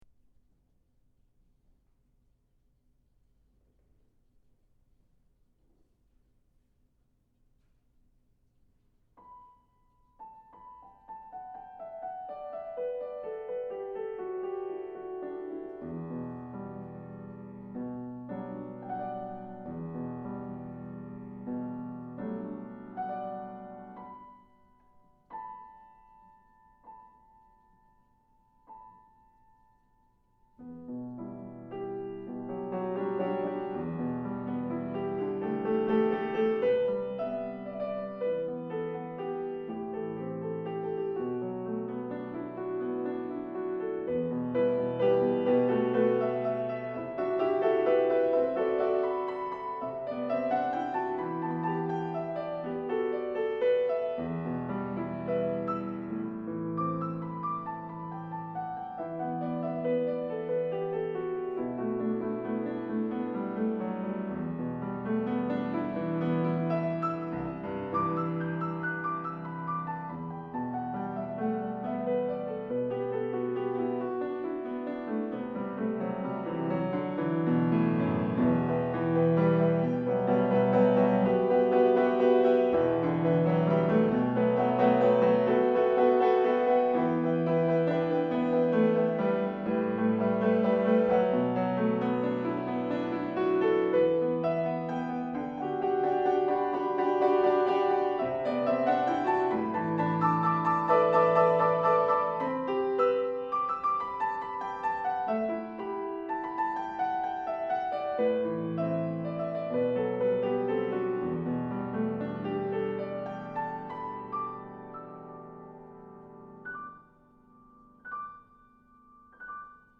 for Piano (2013)
Counter melodies descend, marked "flowing."
The principal theme returns, with intensity.
There is sorrow in the dissonances.